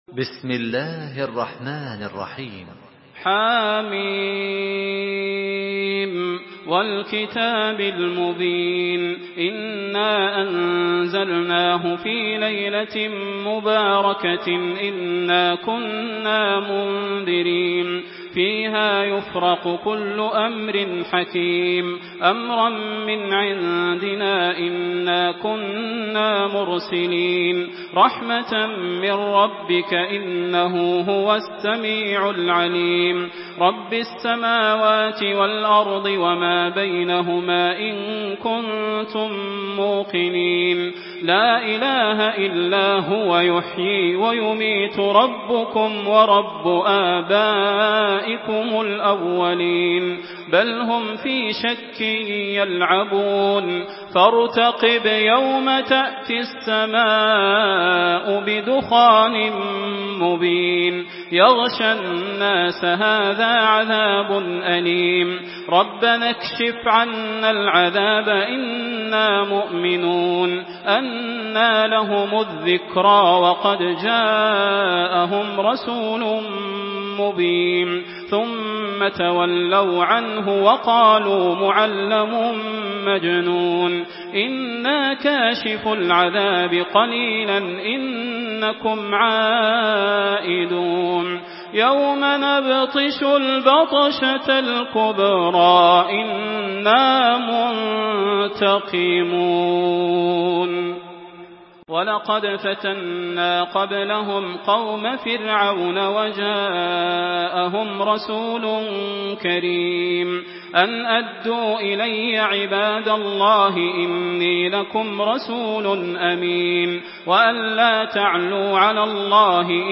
Surah Ad-Dukhan MP3 in the Voice of Makkah Taraweeh 1427 in Hafs Narration
Murattal